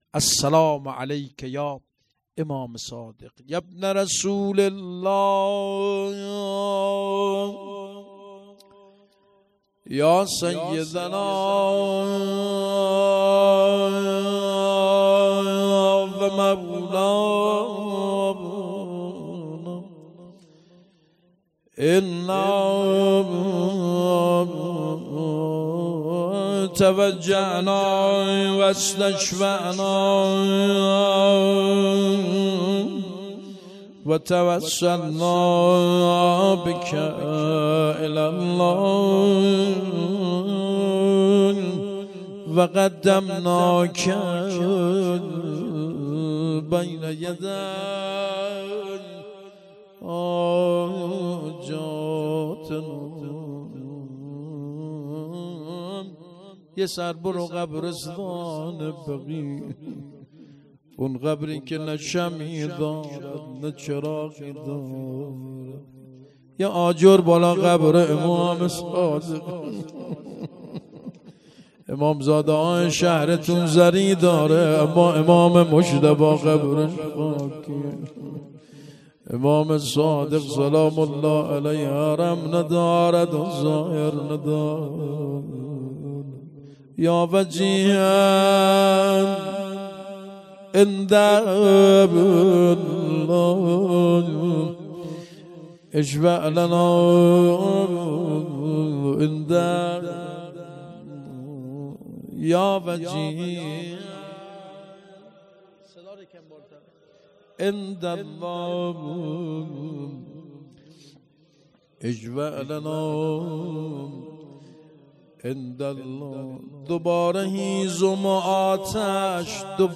مراسم شهادت امام صادق علیه السلام اردیبهشت ۱۴۰۴